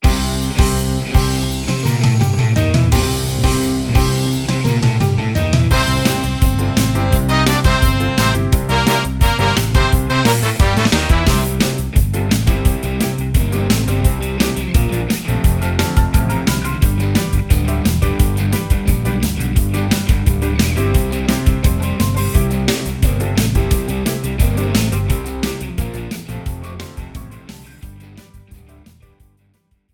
This is an instrumental backing track cover.
• With Backing Vocals
• No Fade